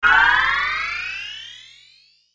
Звук бонуса ускорение